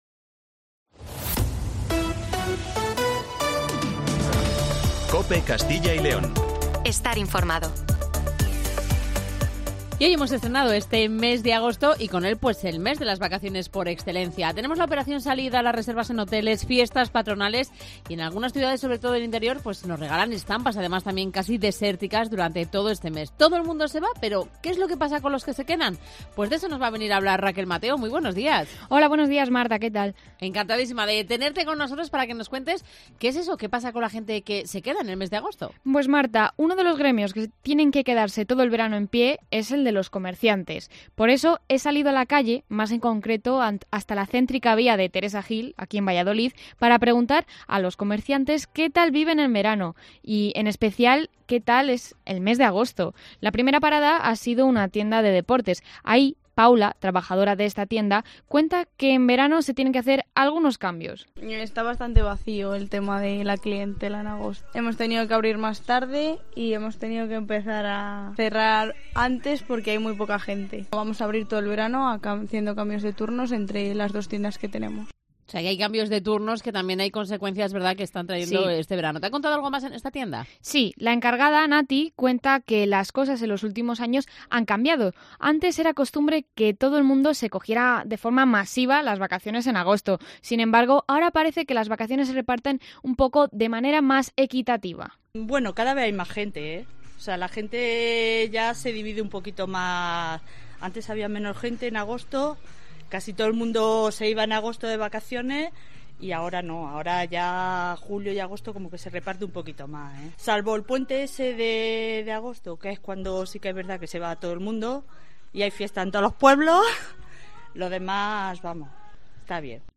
AUDIO: ¿Qué pasa con los trabajadores que se quedan en la ciudad en agosto? Salimos a la calle para conocer los testimonios de los comerciantes.